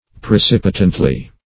Search Result for " precipitantly" : The Collaborative International Dictionary of English v.0.48: Precipitantly \Pre*cip"i*tant*ly\, adv.